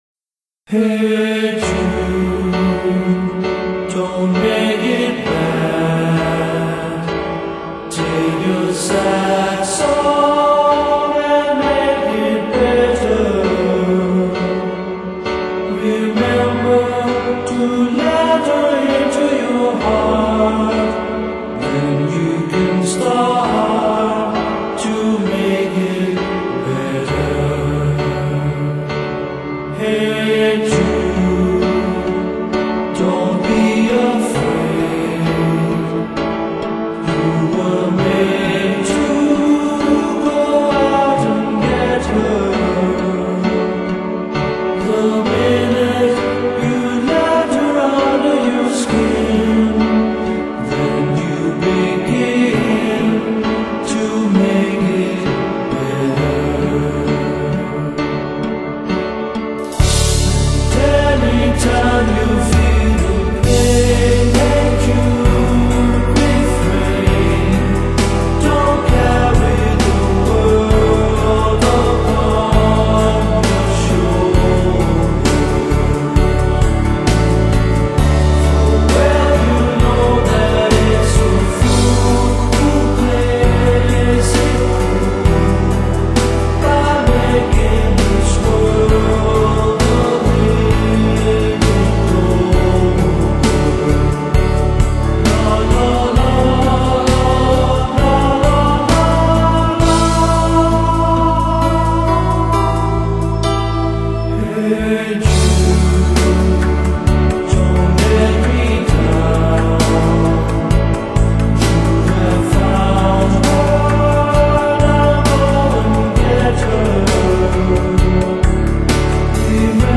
与newage的融合是最大的亮点
整张专集突出的依然是圣咏式的演唱